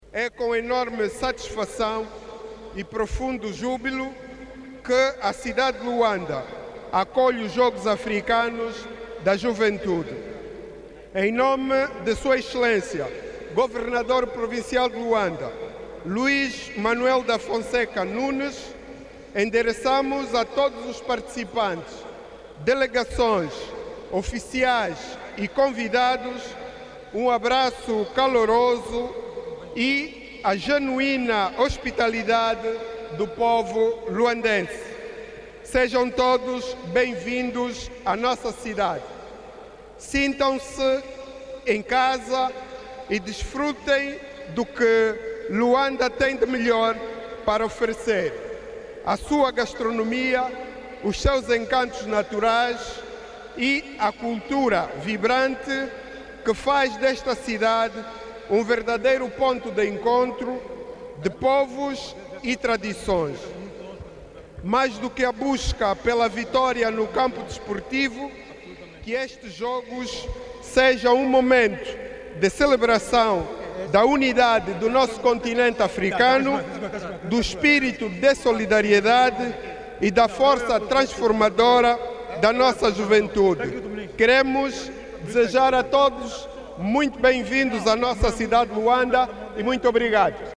Discursos chamando atenção para o respeito aos princípios do olimpismo marcou no Estádio “11 de Novembro”, cerimónia oficial de abertura dos 4ºs Jogos Africanos da Juventude de Luanda e Bengo